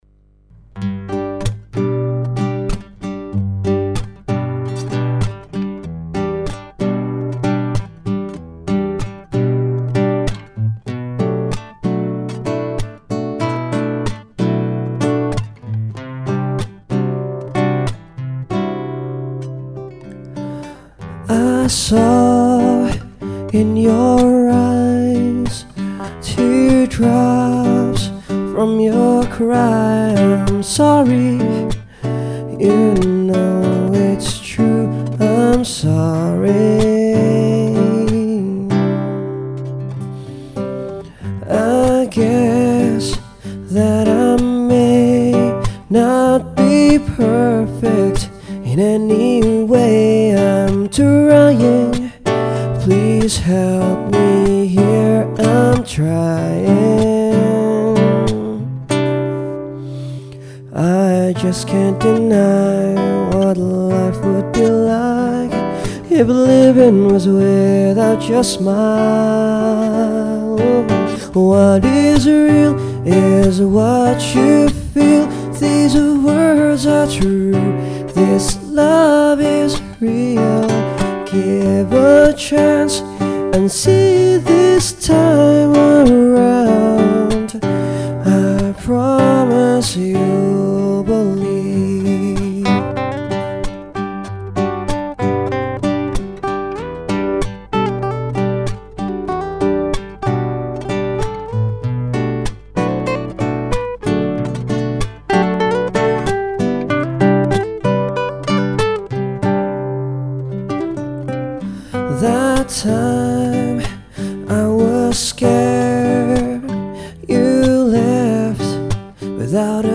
recorded at Dubai Marina